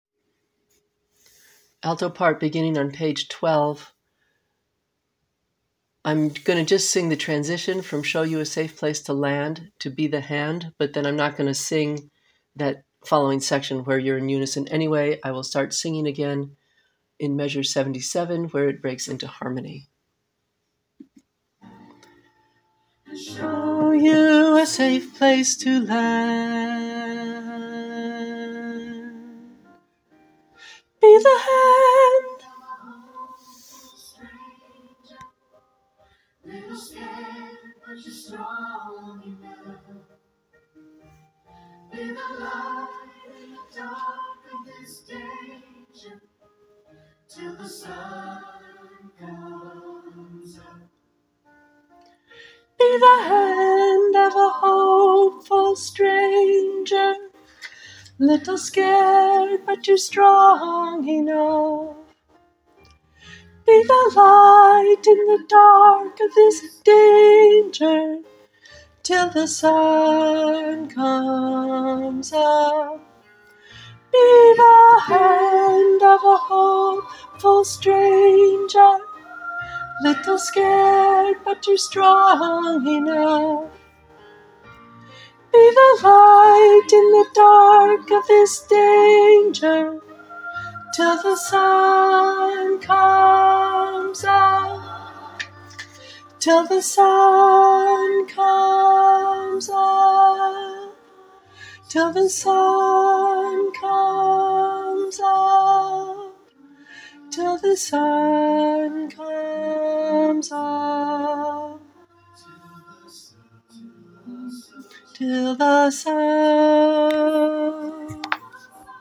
[Choir] Practice recordings - be the hand
Name: alto be the hand.mp3